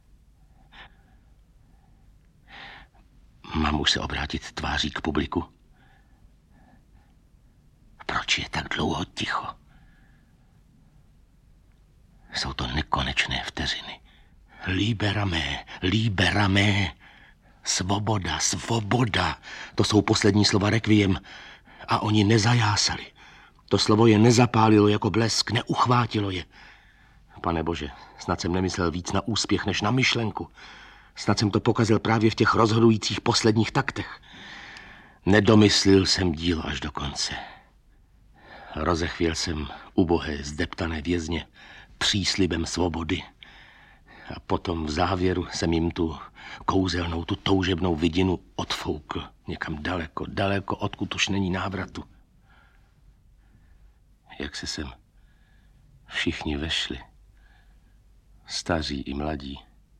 Audiobook
Read: Václav Voska